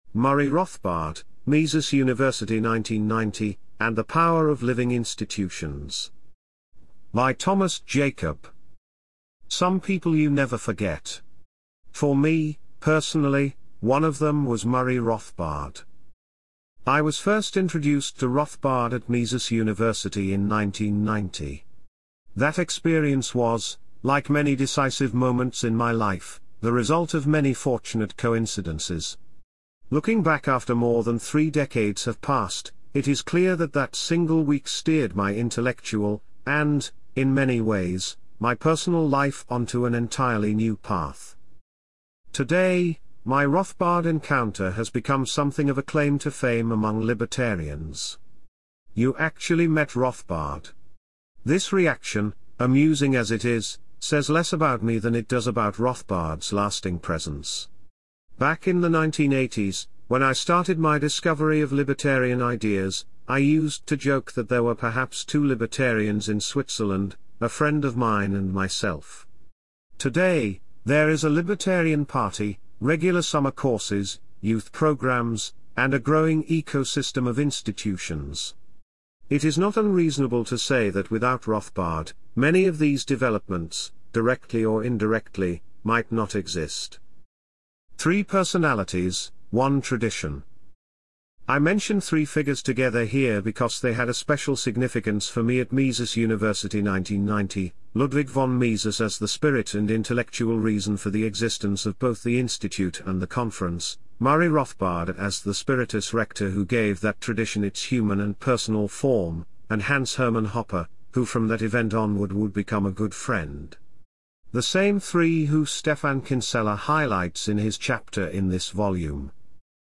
AI-assisted audio narration of the main chapters of Rothbard at 100: A Tribute and Assessment (Papinian Press and The Saif House, 2026) is available at this PFS Youtube Playlist; the mp3 files may also be downloaded in this zip file.